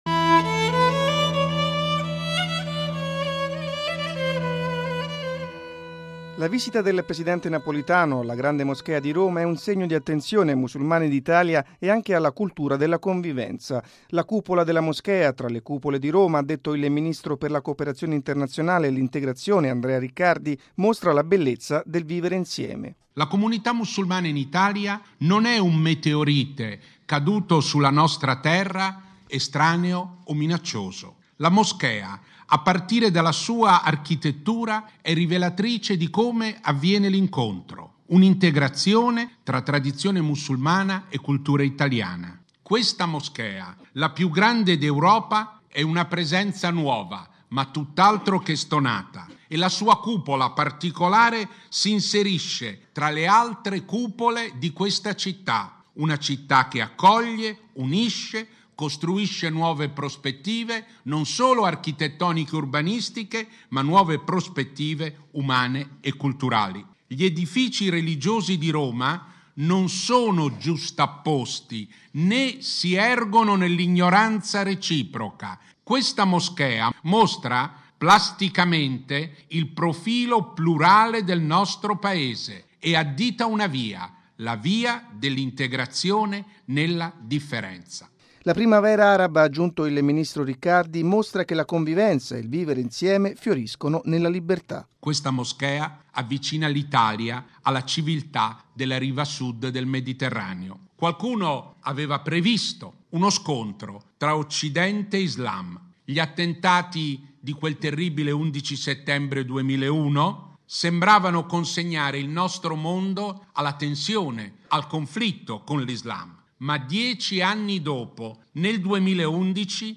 (Parole in arabo)